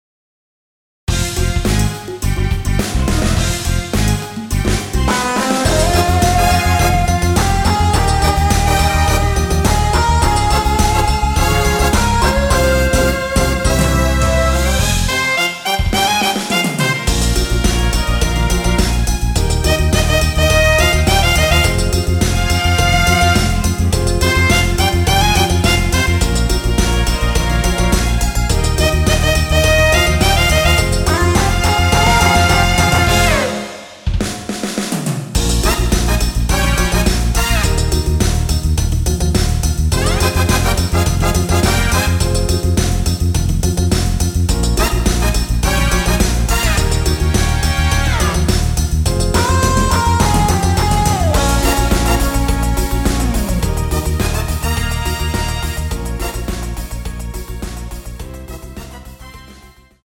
원키에서(+5)올린 MR입니다.
Fm
앞부분30초, 뒷부분30초씩 편집해서 올려 드리고 있습니다.